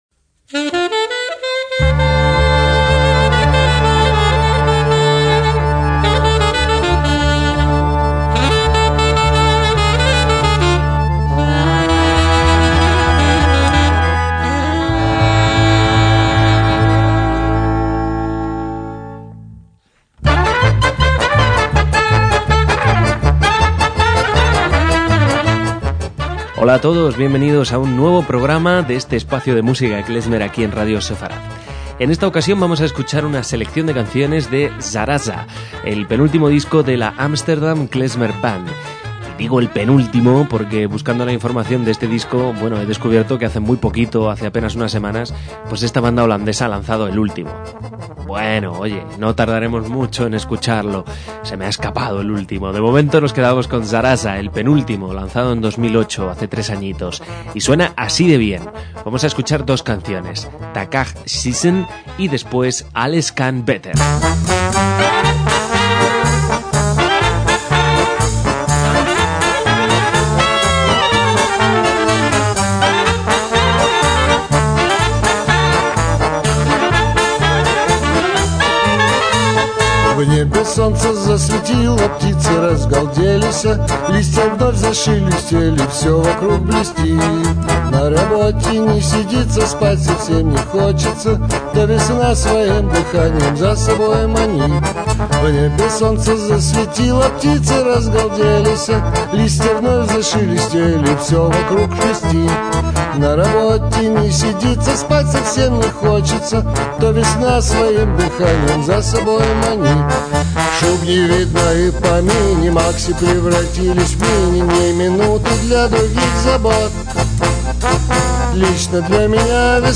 MÚSICA KLEZMER
contrabajo
saxo contralto y voz
percusión y voz
trompeta
trombón
clarinetes